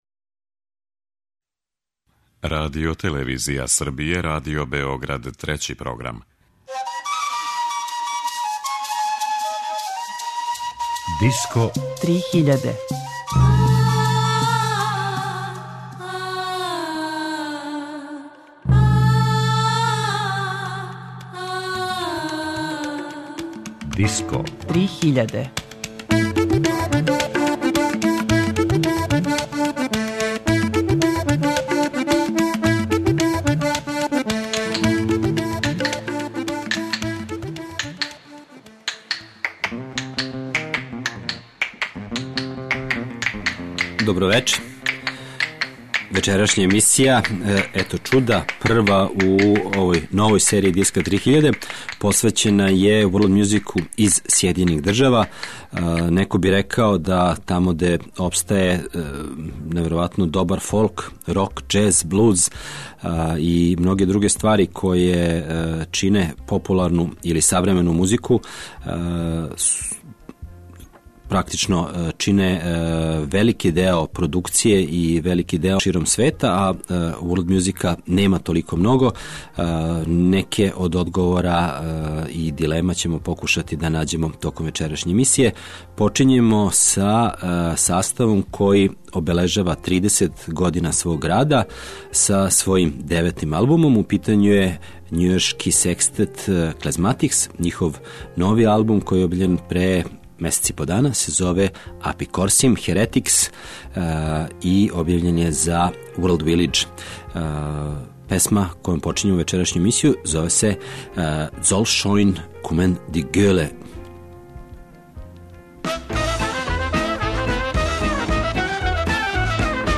Светска музика Сједињених Америчких Држава